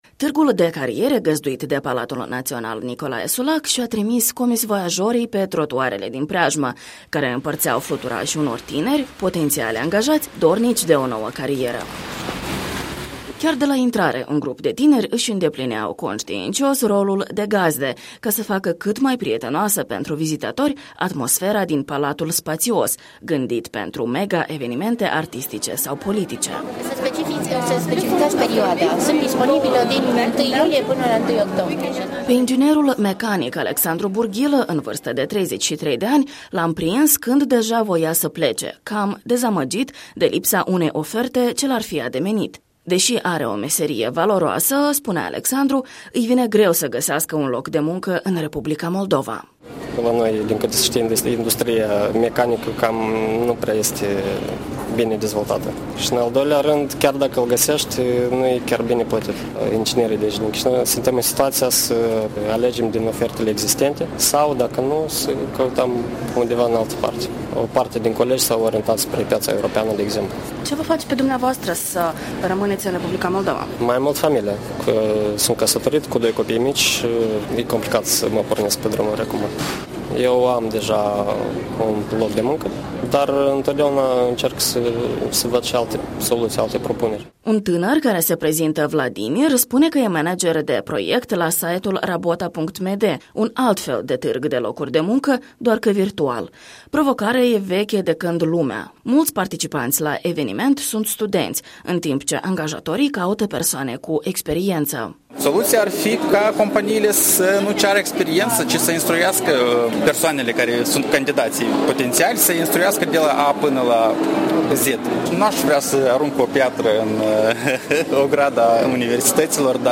La Chișinău s-a desfășurat o nouă ediție a Târgului de Cariere, la care au fost invitate persoane care-și caută un loc de muncă, o instituție pentru stagiu de practică sau de voluntariat.